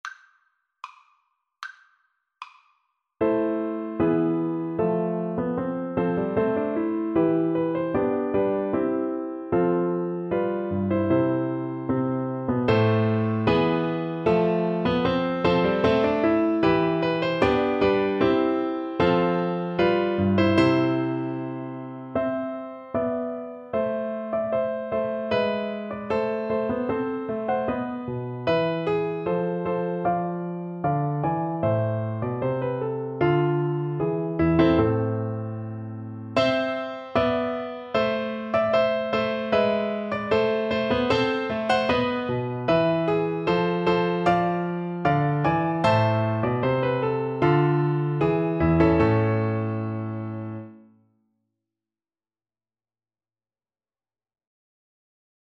2/2 (View more 2/2 Music)
A minor (Sounding Pitch) (View more A minor Music for Flute )
Classical (View more Classical Flute Music)
lully_marche_ceremonie_turcs_FL_kar1.mp3